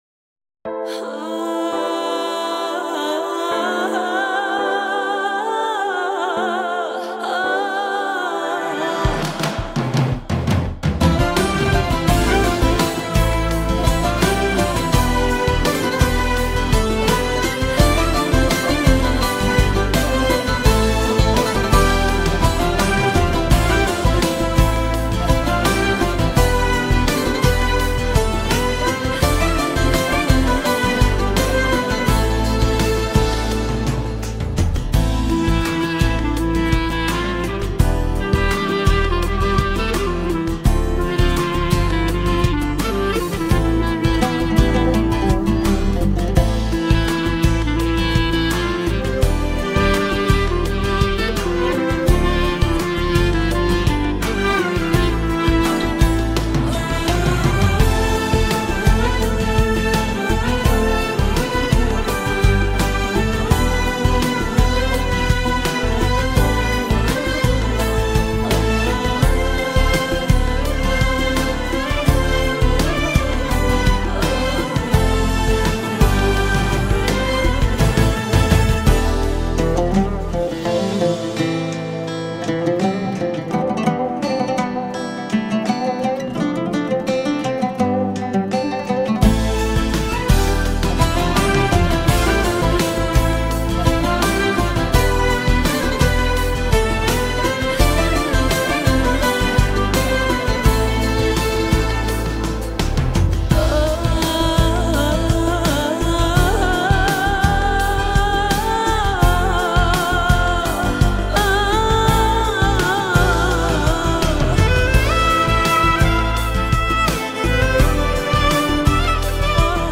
بی کلام